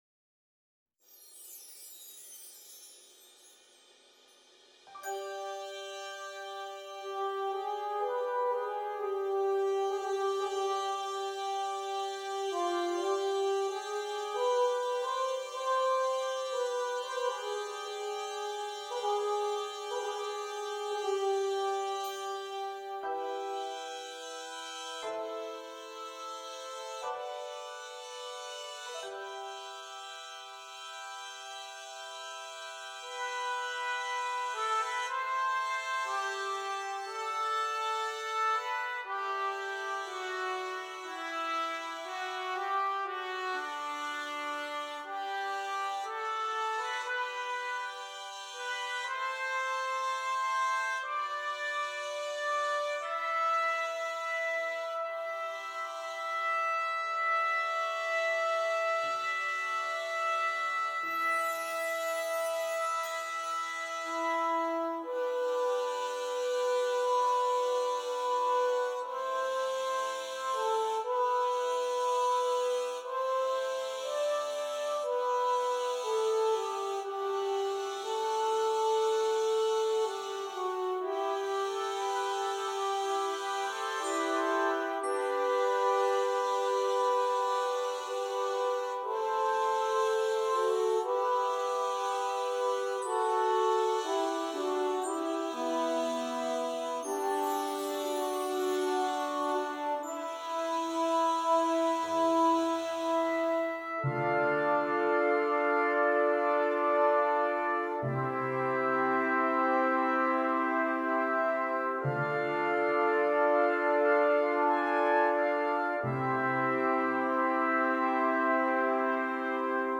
20 Trumpets and Percussion